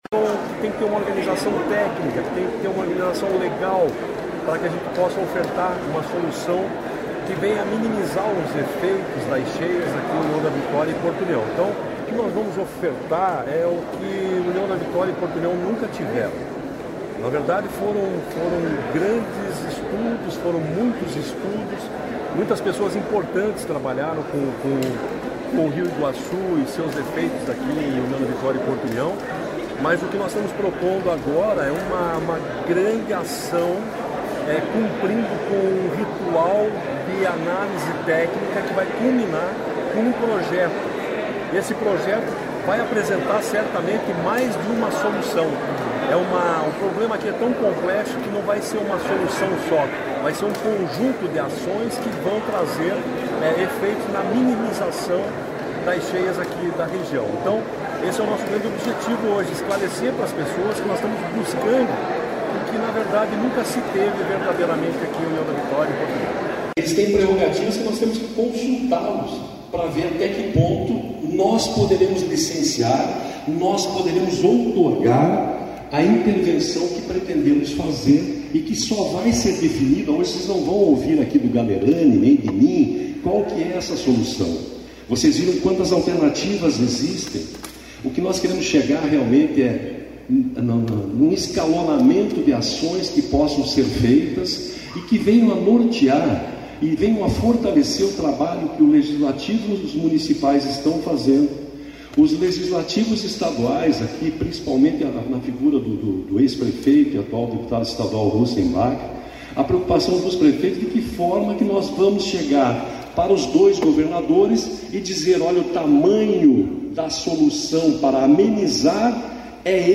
Sonora do diretor-presidente do IAT, Everton Souza, sobre a audiência pública para solução das cheias do Rio Iguaçu na área de União da Vitória